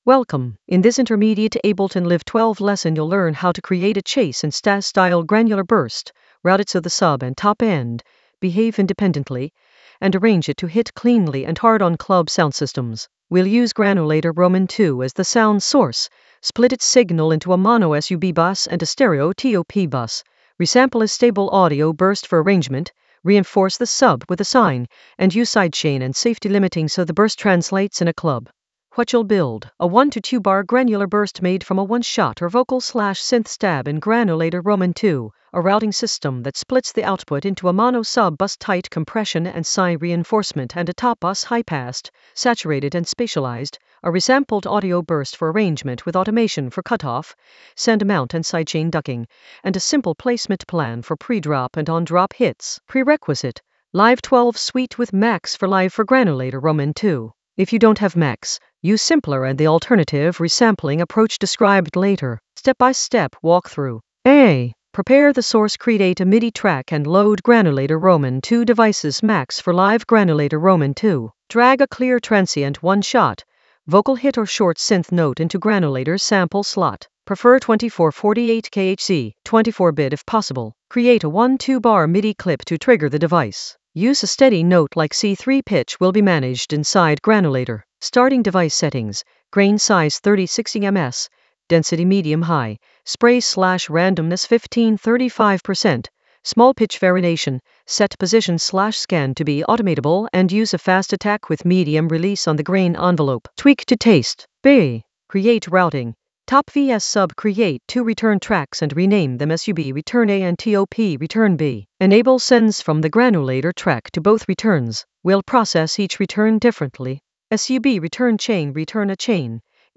An AI-generated intermediate Ableton lesson focused on Chase & Status granular burst: route and arrange in Ableton Live 12 for sub-heavy soundsystem pressure in the Sampling area of drum and bass production.
Narrated lesson audio
The voice track includes the tutorial plus extra teacher commentary.